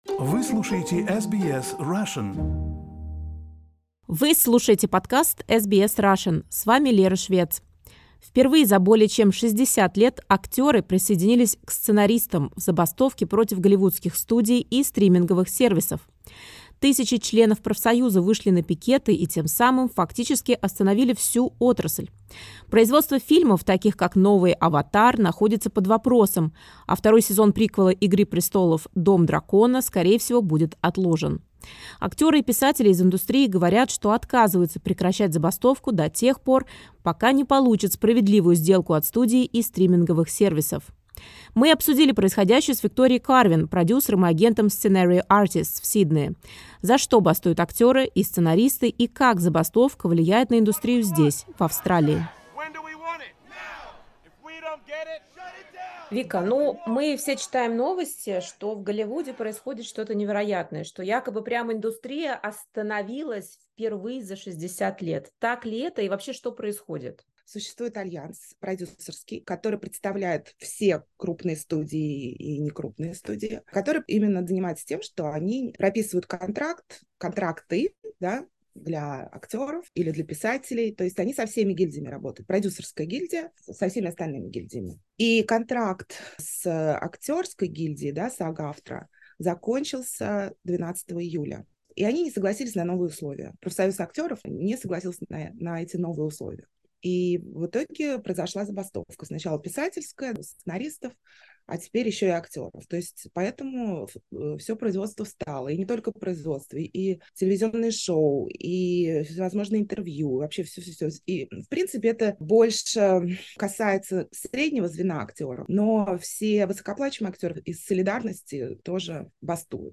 Впервые за более чем 60 лет актеры присоединились к сценаристам в забастовке против голливудских студий и стриминговых сервисов. Тысячи членов профсоюза вышли на пикеты, и тем самым фактически остановили всю отрасль. SBS Russian обсудили происходящее с продюсером